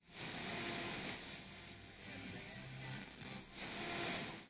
短波无线电噪音
描述：短波无线电噪声。汇编声音
Tag: 电子 无线电 短波 调谐器 频率 静态 频率 AM 干扰 调谐 AM-无线电 噪声